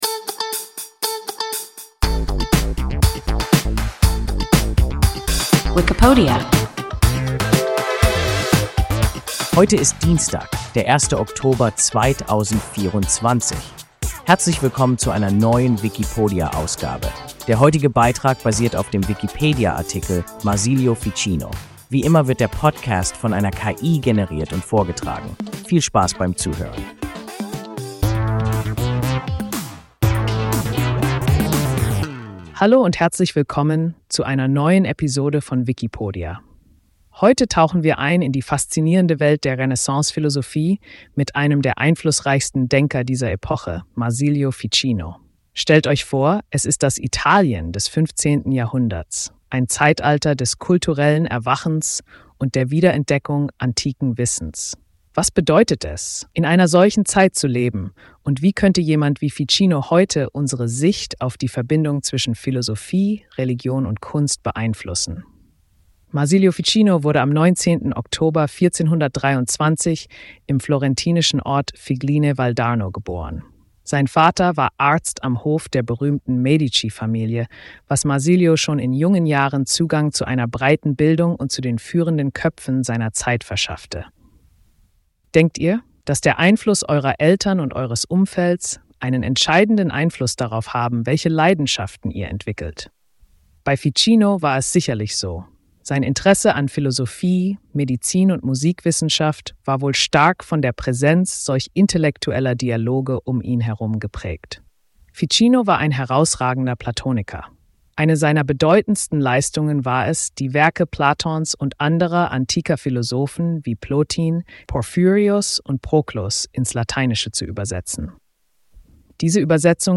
Marsilio Ficino – WIKIPODIA – ein KI Podcast